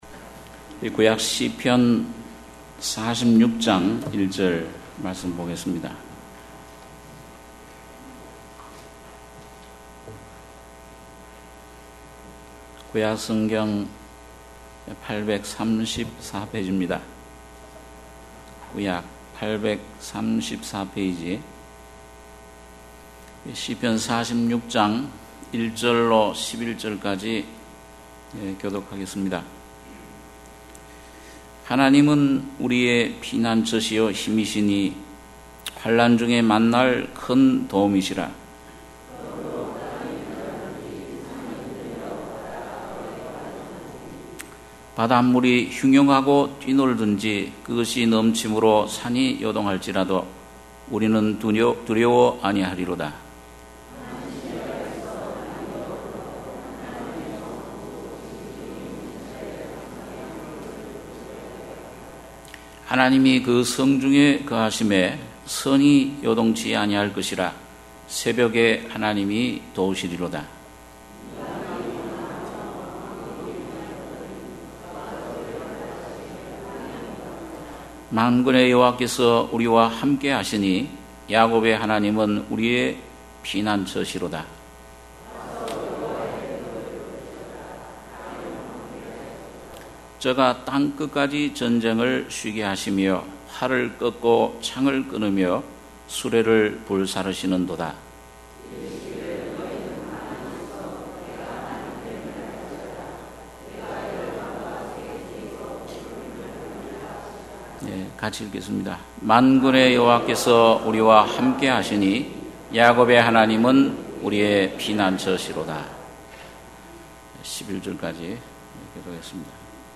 주일예배 - 시편 46편 1-11절